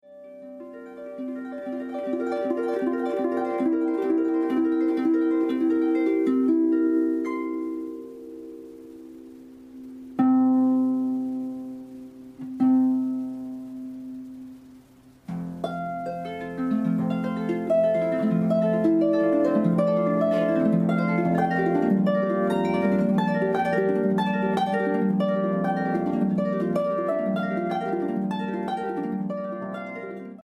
South East based Solo Harpist
• Stunning black Italian Apollo harp which has a lush, warm, and full sound
Harp